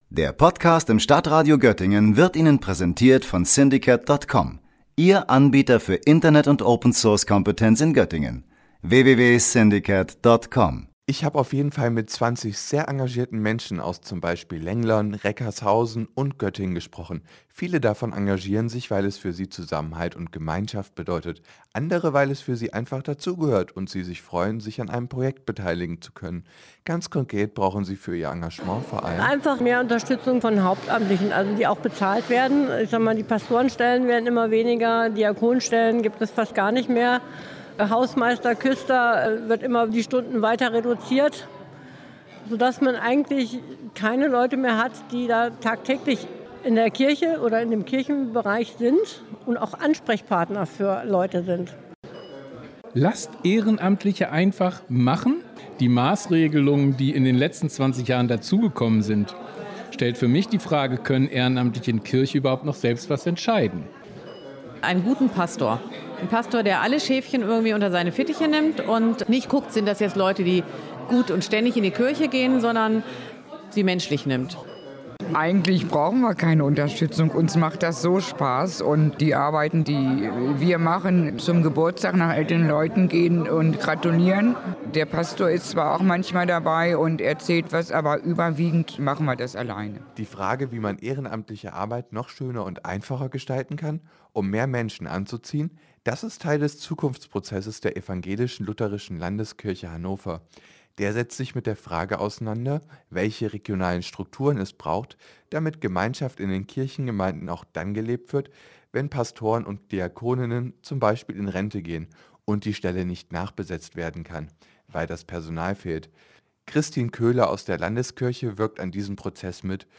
- Diskussionsabend in Lenglern - StadtRadio Göttingen